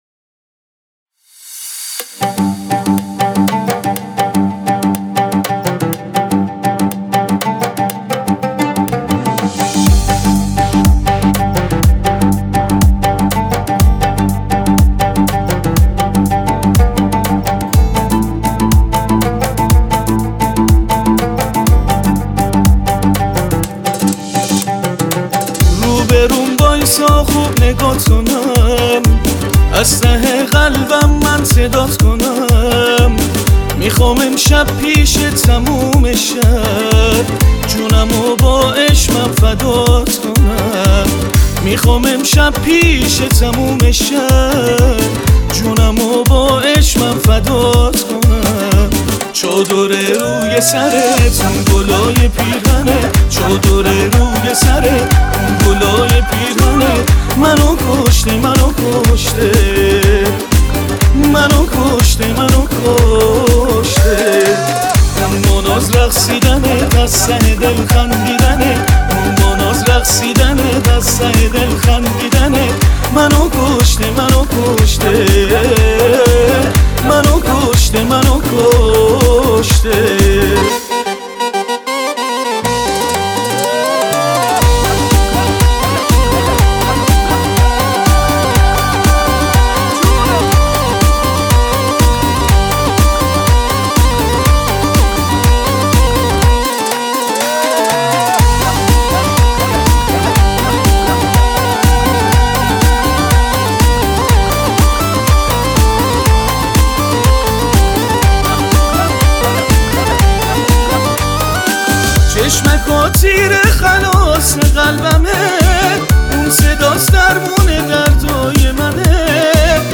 آهنگ